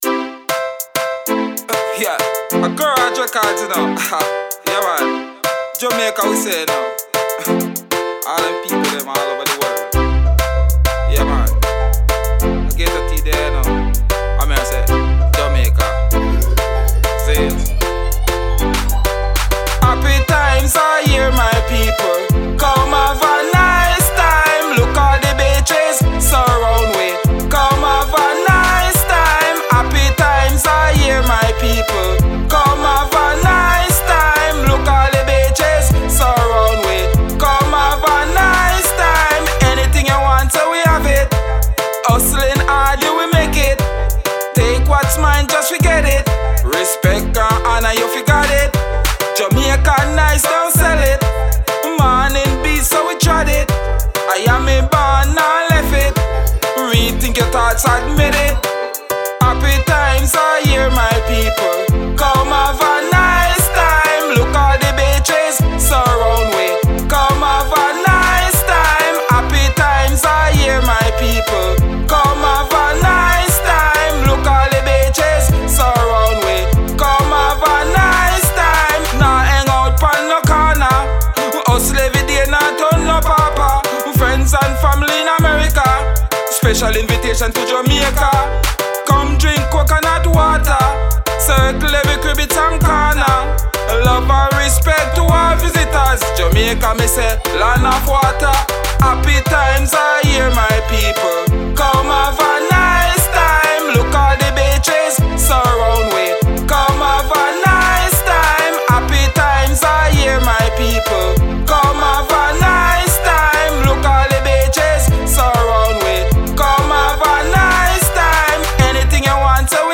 retro dancehall style vocals